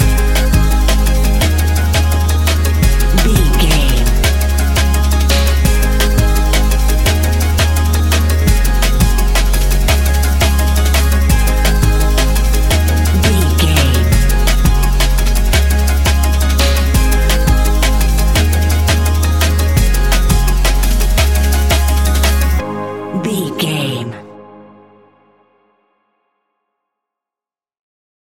Ionian/Major
G♯
electronic
techno
trance
synths
synthwave
instrumentals